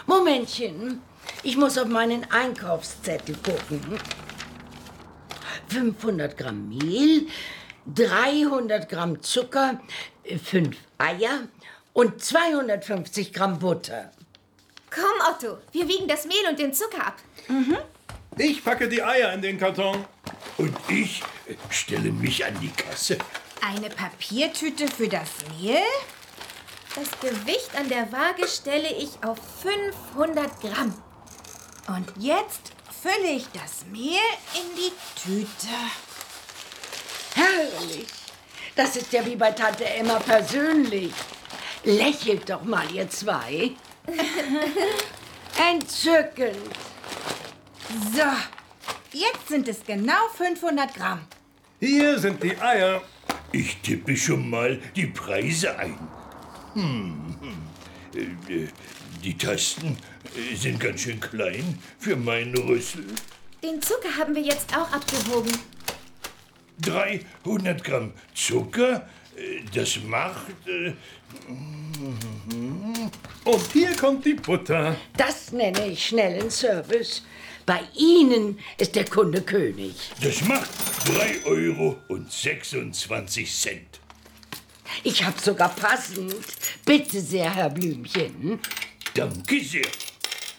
Ravensburger Benjamin Blümchen - ...im Tante-Emma-Laden ✔ tiptoi® Hörbuch ab 3 Jahren ✔ Jetzt online herunterladen!